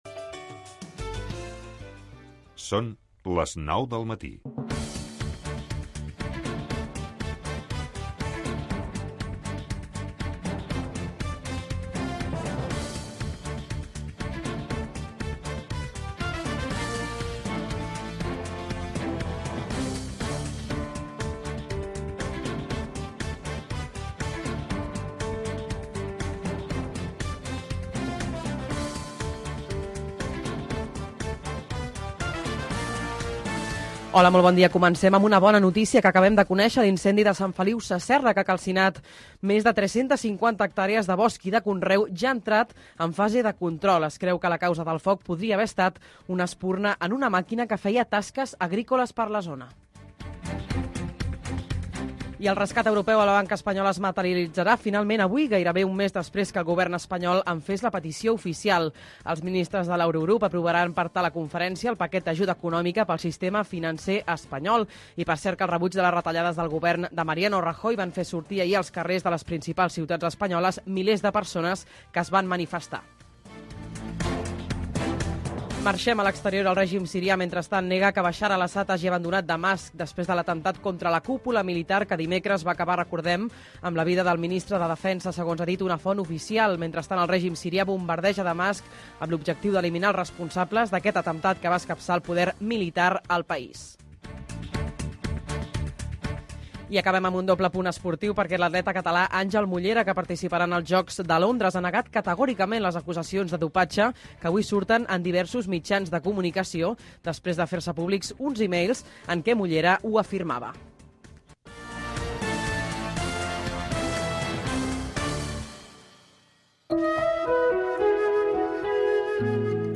El primer cafè. Informació, actualitat, espais, 2 hores d'un magazin matinal